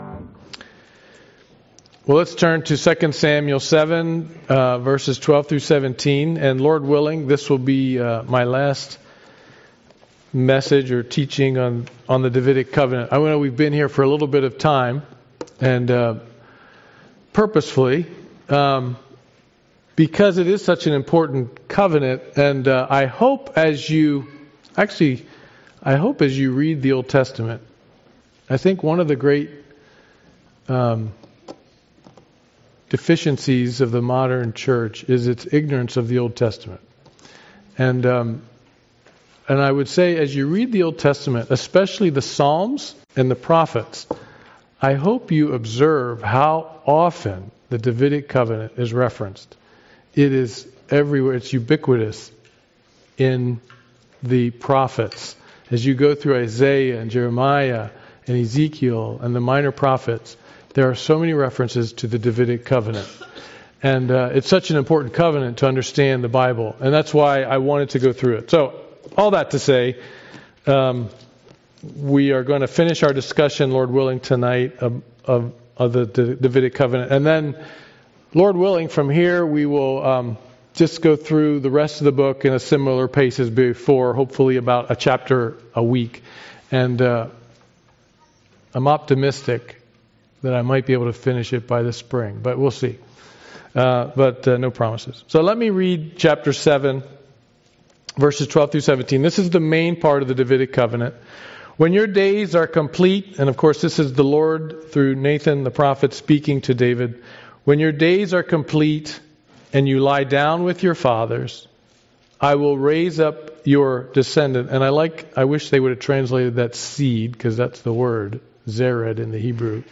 Passage: 2 Samuel 7 Service Type: Sunday Evening Worship « Resisting the Devil